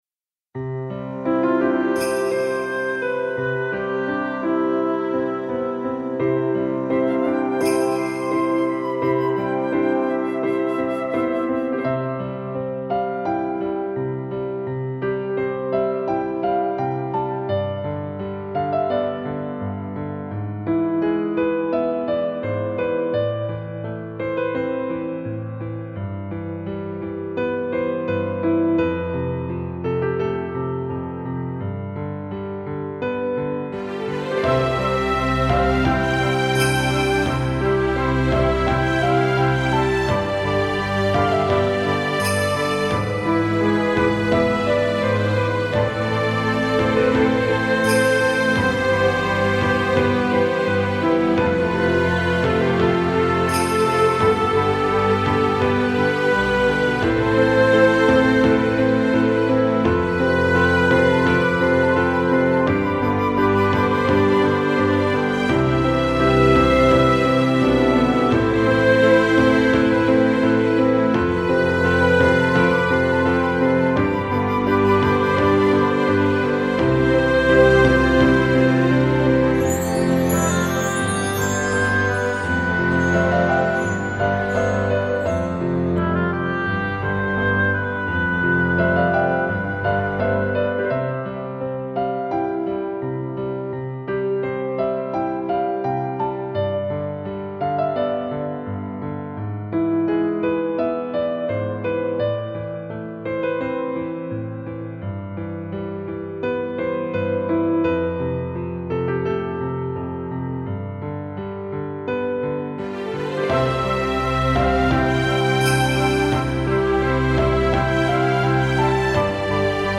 2017.11 | ほのぼの | ニューエイジ | 2分32秒/2.32 MB